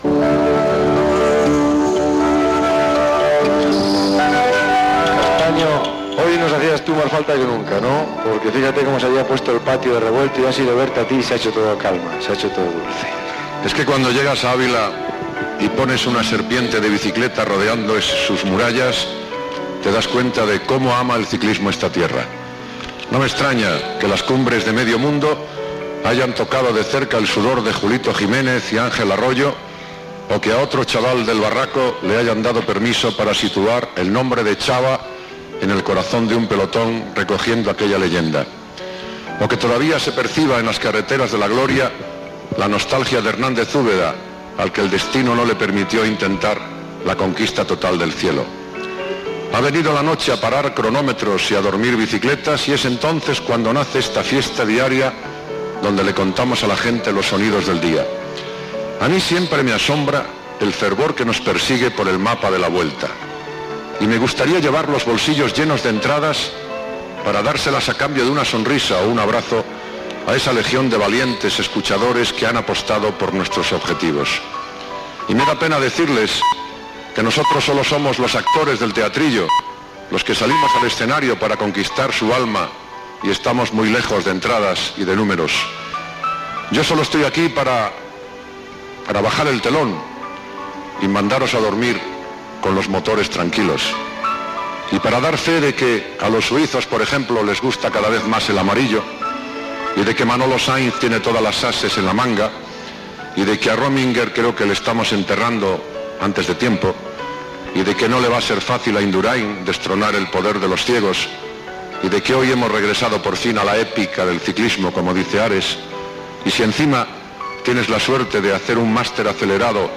Espai fet cara al públic a Àvila on ha acabat una etapa de la Vuelta Ciclista a España. Comentari final del programa
Esportiu